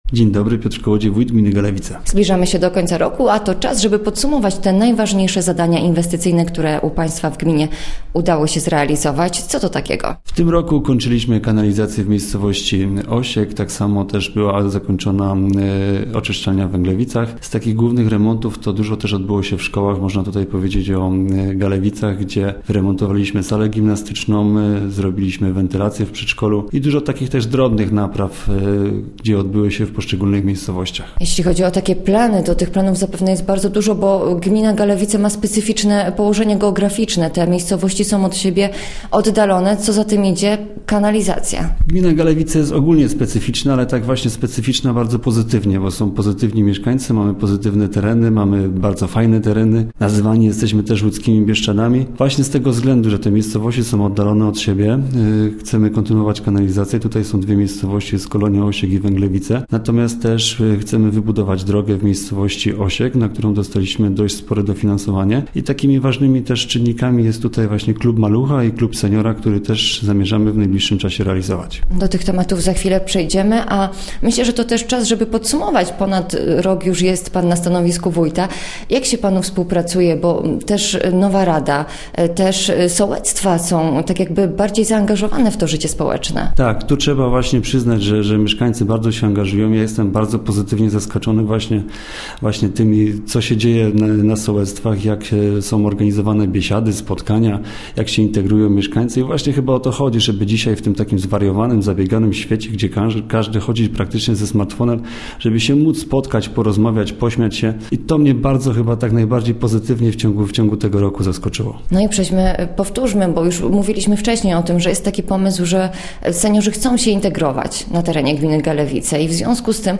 Gościem Radia ZW był wójt gminy Galewice, Piotr Kołodziej